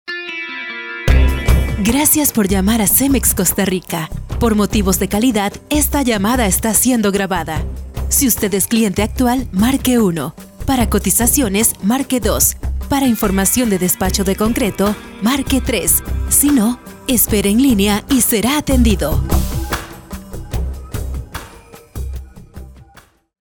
Locutora Comercial, locutora de Noticias, editora y productora de radio.
Sprechprobe: eLearning (Muttersprache):